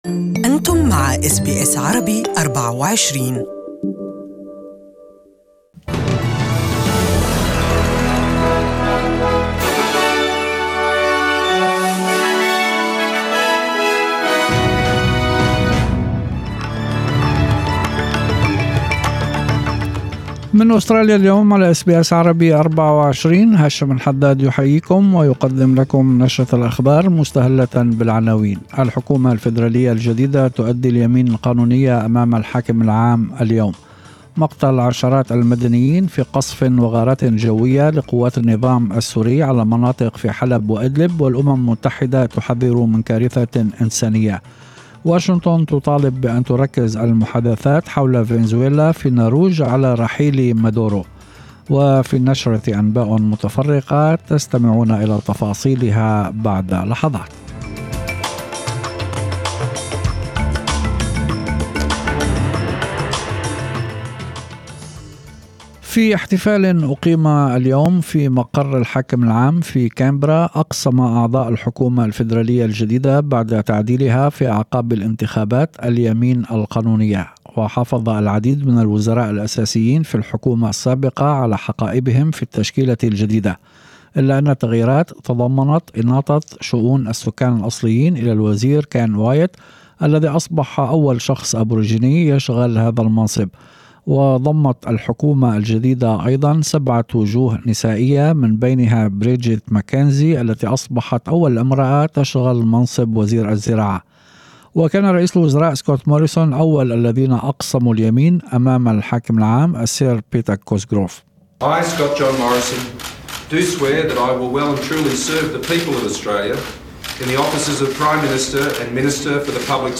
نشرة أخبار المساء: اعتقال العشرات في حملة أمنية لمكافحة المخدرات في سيدني
هذه الأخبار من نشرة أخبار المساء التي يمكن الاستماع إليها كاملة في التقرير الإذاعي المرفق.